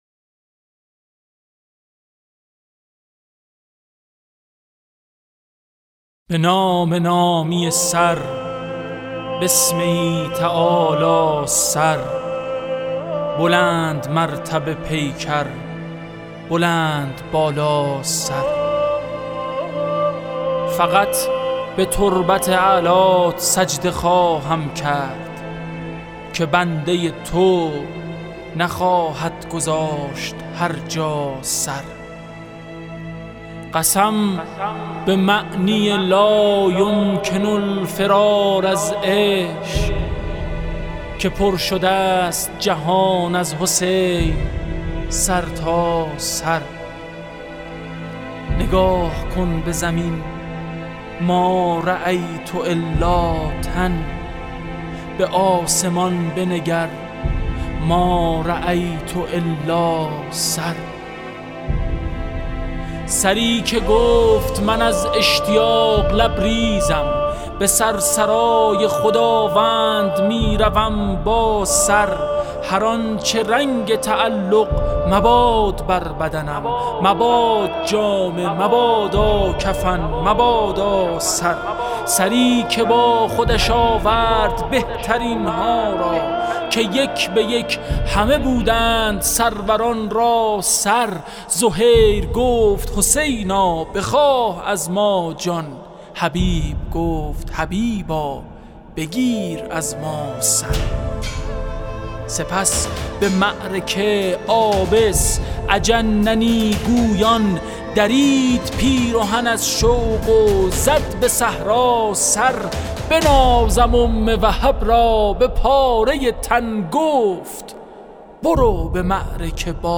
به مناسبت ایام سوگواری اباعبدالله الحسین (ع)، شعرخوانی سید حمیدرضا برقعی، شاعر آئینی کشور از کتاب «تحریرهای رود» ارائه می‌شود. این مجموعه شامل اشعار منتخب عاشورایی است که به همت موسسه فرهنگی هنری «رسانه‌ی آفتاب» منتشر شد.